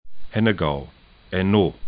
'hɛnəgau